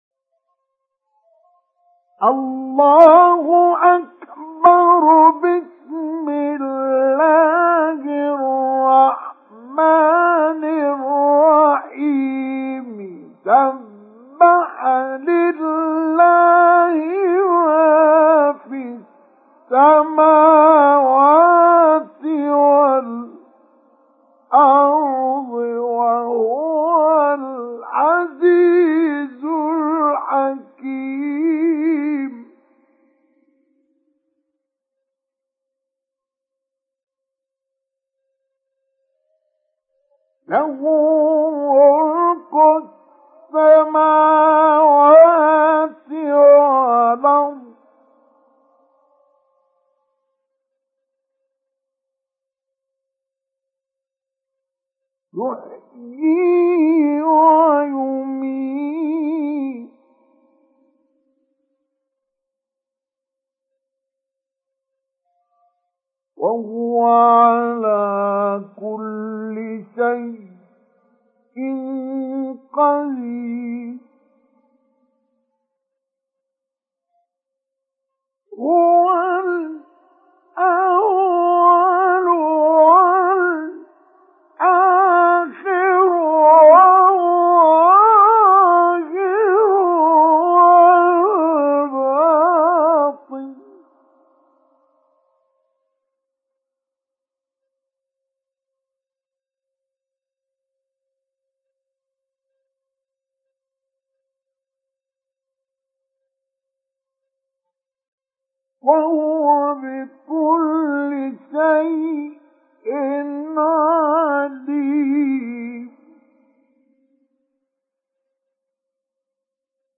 سُورَةُ الحَدِيدِ بصوت الشيخ مصطفى اسماعيل